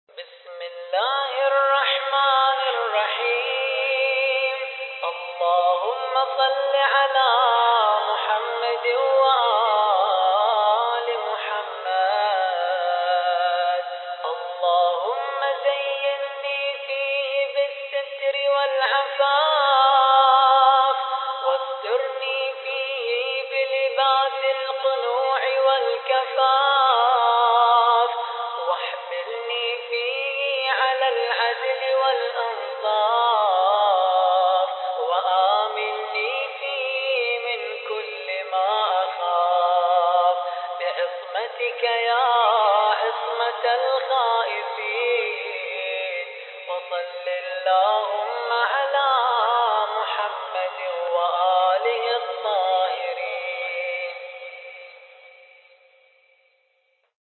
الخطیب: الرادود